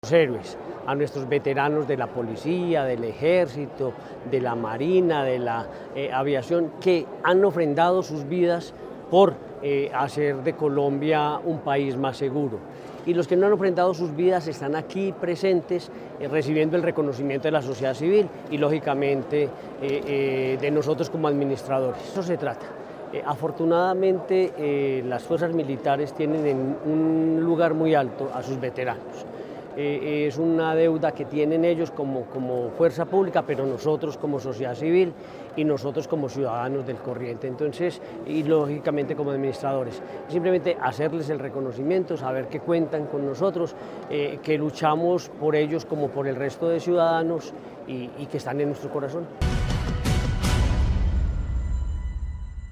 En compañía de los comandantes del Ejército y la Policía Nacional de la región, se desarrolló un acto conmemorativo del Día del Veterano de la Fuerza Pública en Caldas.
Gobernador de Caldas, Henry Gutiérrez Ángel.